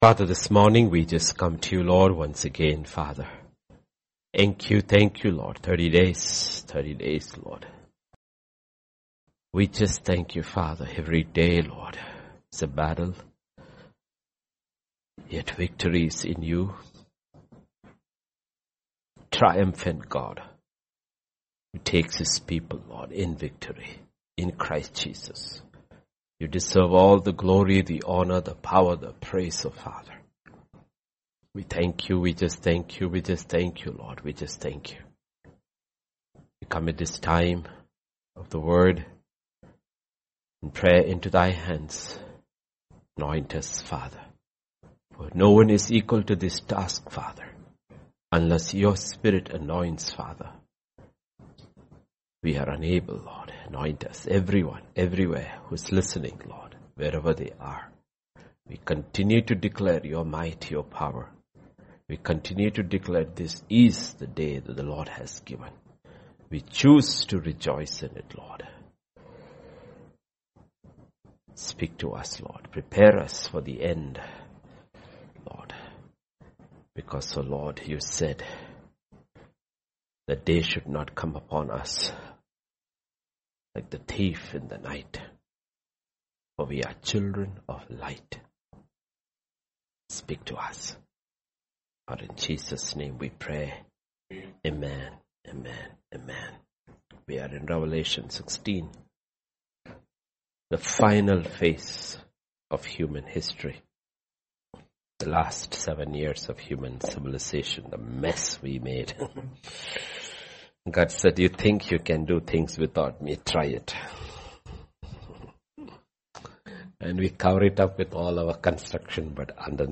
Grace Tabernacle Church Hyderabad - Sermons podcast